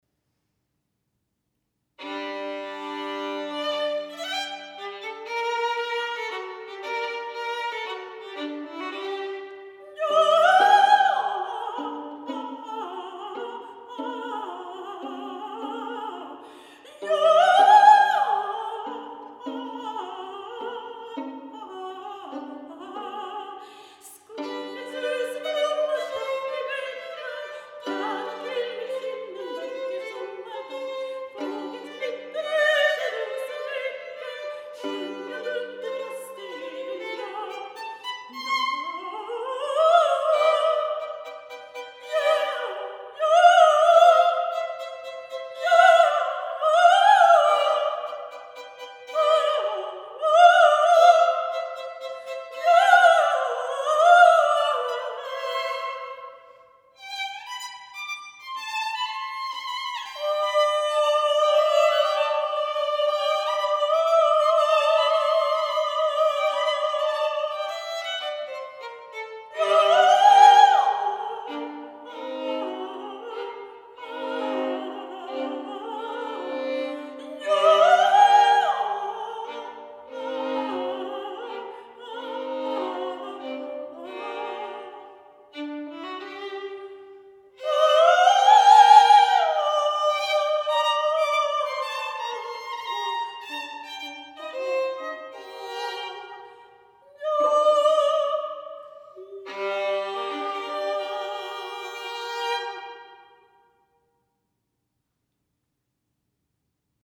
Mezzosopran
Violin
Polska